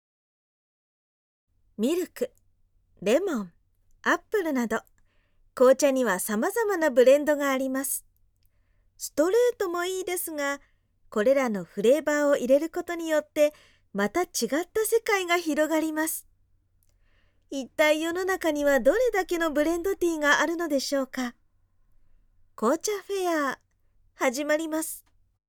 ボイスサンプル
明るいナレーション